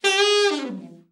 ALT FALL  11.wav